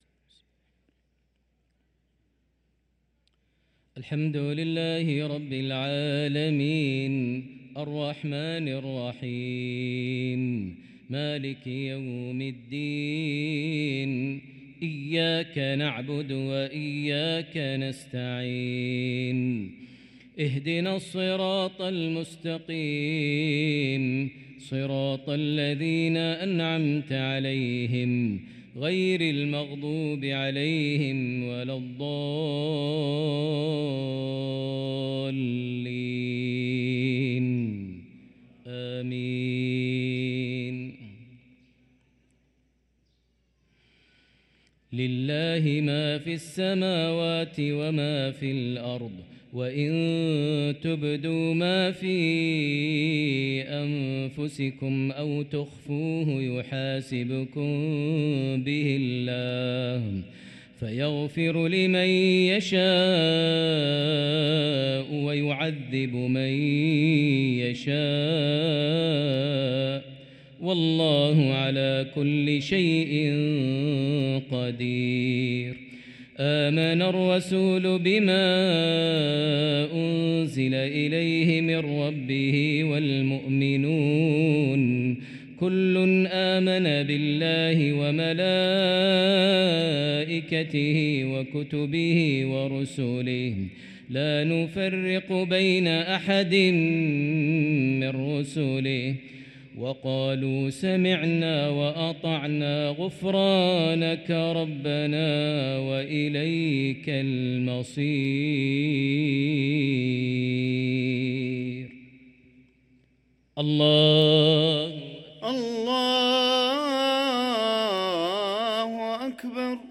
صلاة المغرب للقارئ ماهر المعيقلي 20 ربيع الآخر 1445 هـ
تِلَاوَات الْحَرَمَيْن .